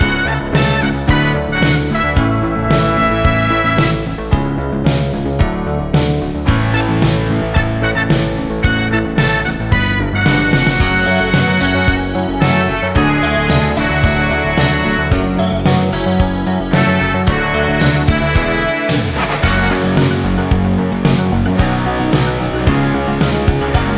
no Backing Vocals Soundtracks 4:07 Buy £1.50